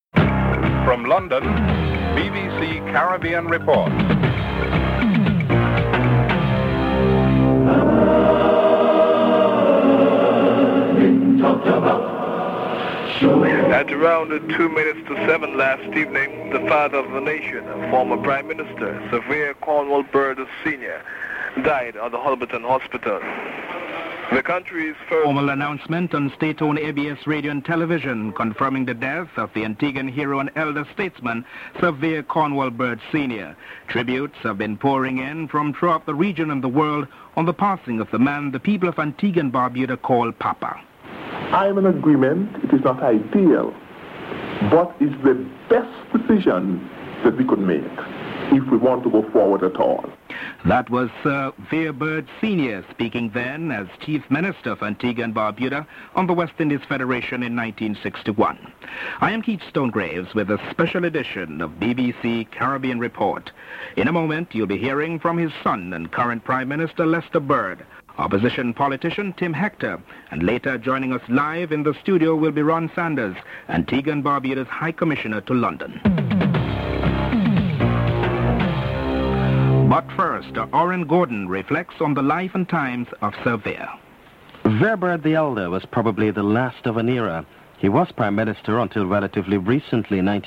Tributes and interviews in this segment are presented by his son and current Prime Minister Lester Bird Junior, opposition leader Leonard Tim Hector, Sir Ronald Sanders Antigua and Barbuda High Commissioner to London, and politicians in the Caribbean region (01:25)